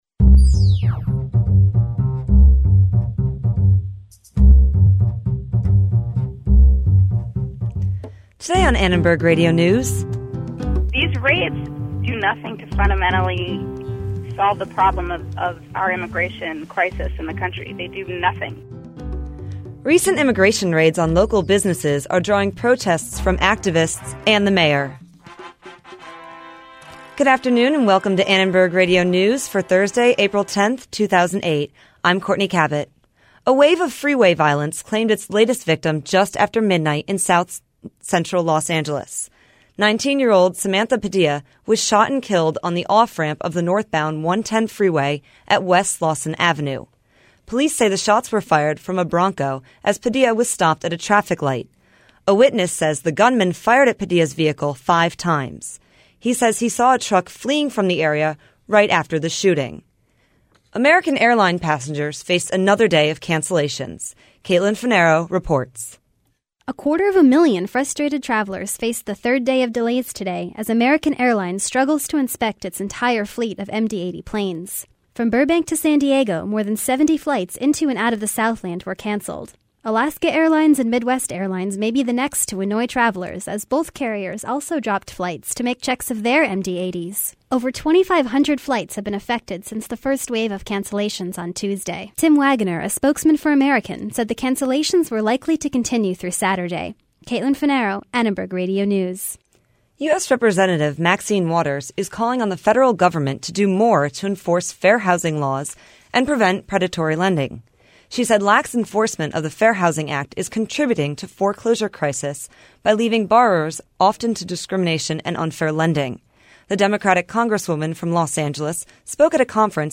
ARN Live Show - April 10, 2008 | USC Annenberg Radio News
Hear a coalition of community groups and unions calling on the mayor to take care of people who need help.